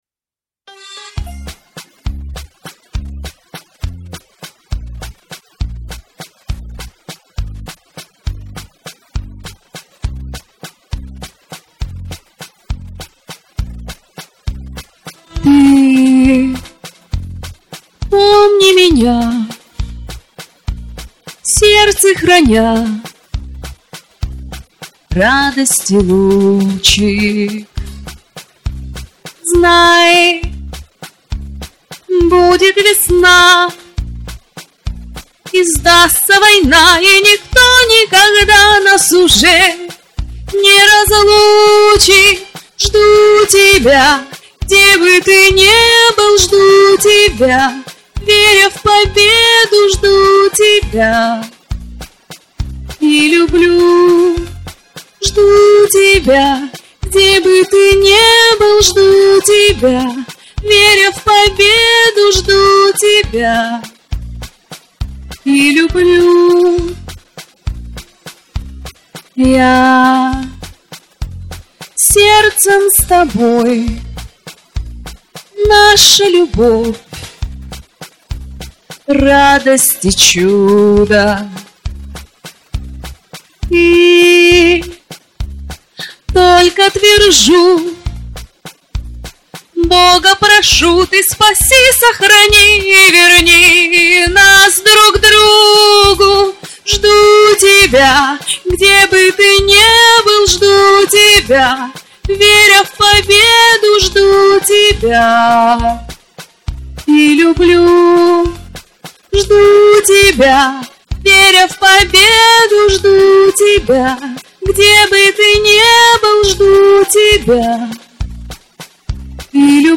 такое впечатление,что музыка была где-то за кадром...